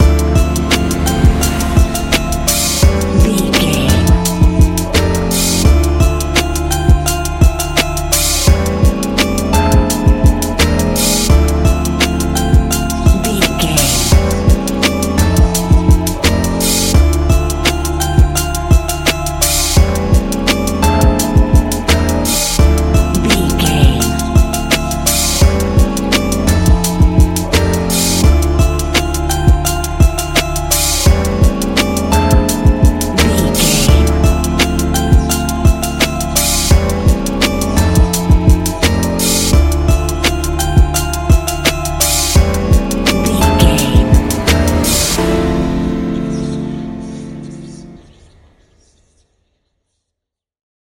Ionian/Major
laid back
sparse
new age
chilled electronica
ambient
atmospheric